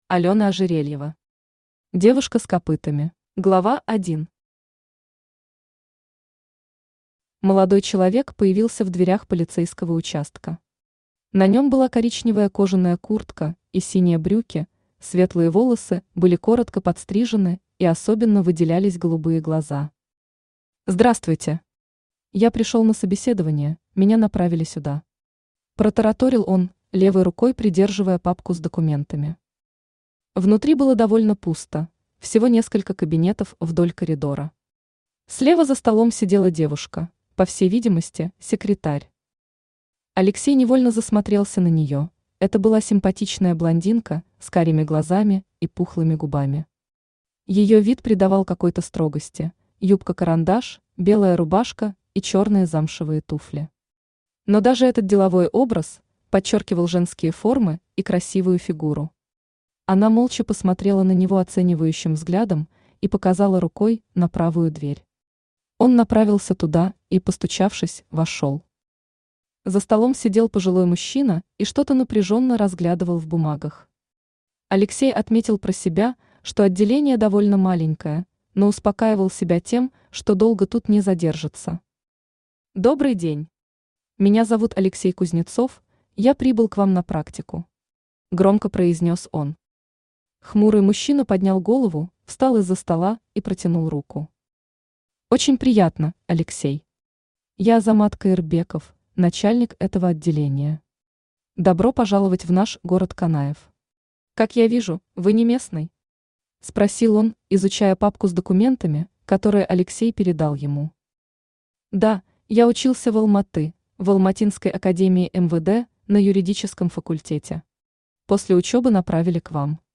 Aудиокнига Девушка с копытами Автор Алёна Игоревна Ожерельева Читает аудиокнигу Авточтец ЛитРес.